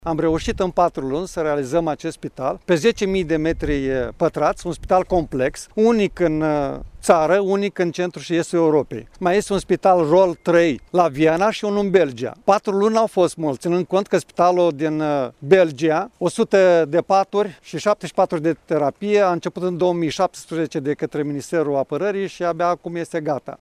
Candidatul PSD la preşedinţia Consiliului Judeţean Iaşi, Maricel Popa, a fost prezent, astăzi, în comuna Leţcani, la inaugurarea celui mai mare spital mobil COVID-19 din Romania, cu 250 de paturi, din care 103 de Terapie Intensivă.